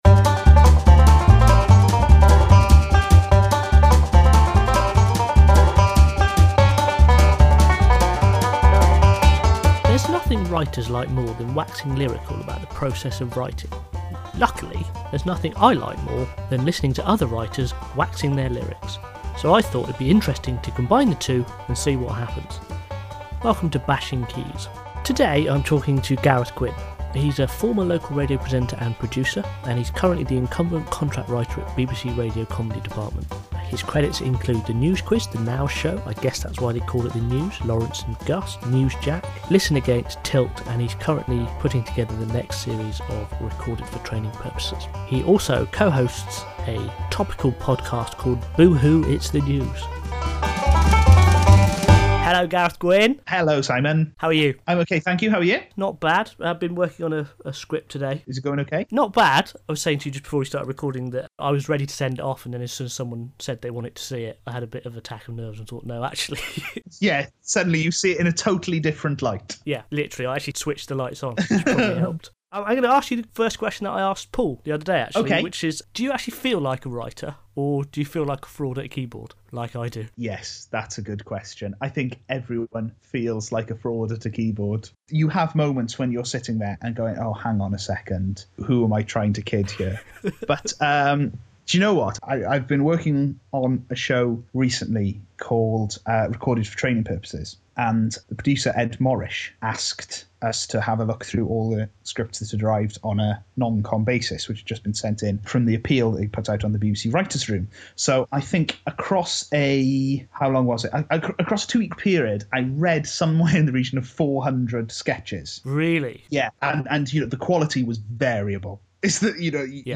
It’s the next installment of my occasional podcast in which I talk writing with other writers.